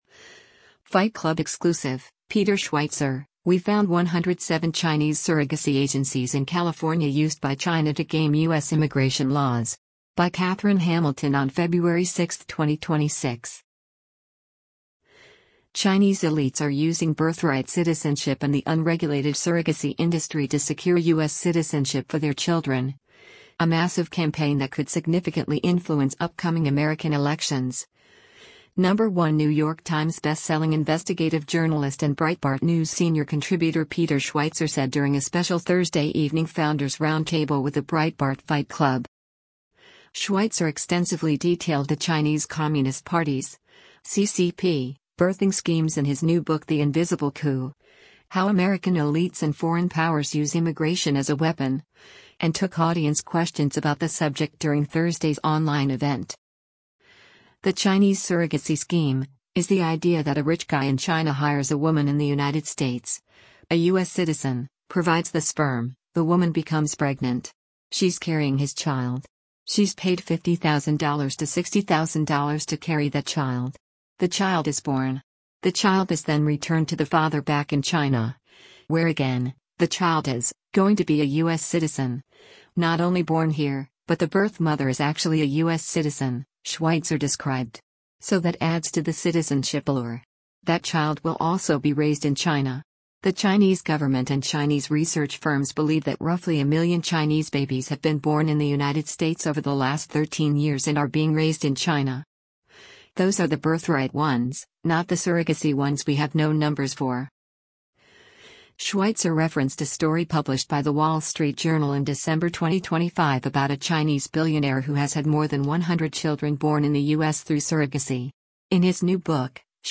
Chinese elites are using birthright citizenship and the unregulated surrogacy industry to secure U.S. citizenship for their children — a massive campaign that could significantly influence upcoming American elections, #1 New York Times bestselling investigative journalist and Breitbart News Senior Contributor Peter Schweizer said during a special Thursday evening Founders Roundtable with the Breitbart Fight Club.
Schweizer extensively detailed the Chinese Communist Party’s (CCP) birthing schemes in his new book The Invisible Coup: How American Elites and Foreign Powers Use Immigration as a Weapon, and took audience questions about the subject during Thursday’s online event.